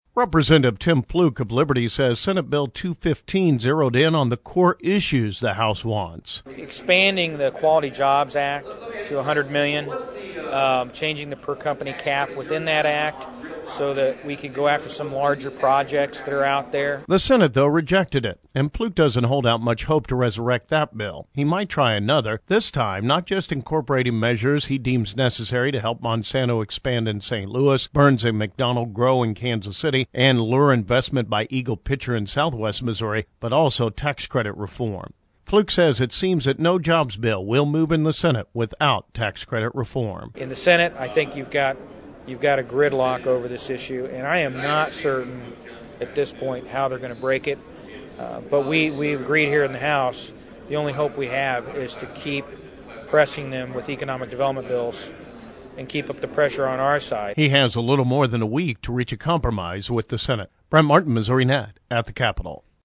"In the Senate, I think you have a gridlock over this issue and I am not certain, at this point, how they are going to break it," Flook says.